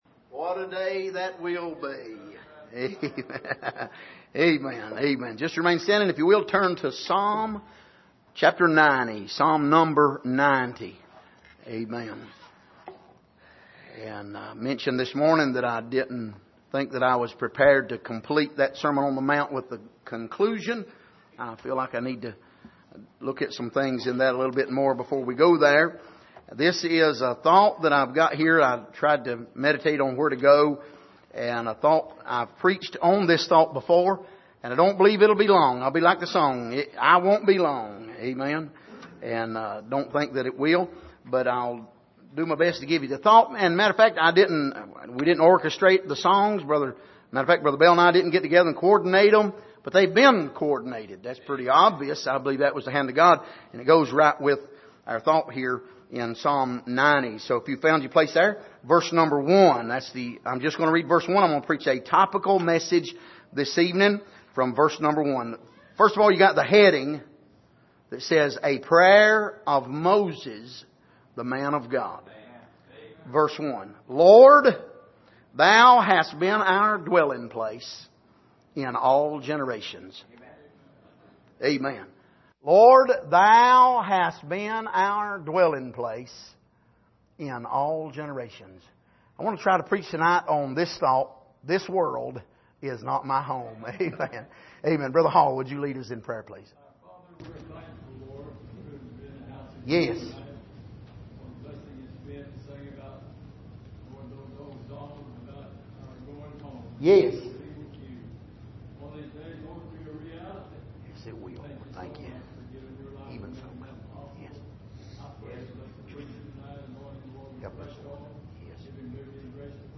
Passage: Psalm 90:1 Service: Sunday Evening